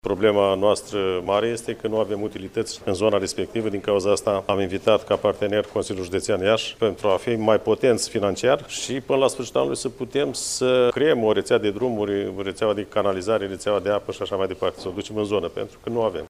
La rândul său, primarul comunei ieşene Miroslava, Dan Niţă, şi-a exprimat speranţa că până la sfârşitul acestui an, în zonele vizate, vor fi completate reţelele de infrastructură rutieră, de apă şi canalizare.